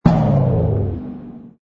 engine_ku_freighter_kill.wav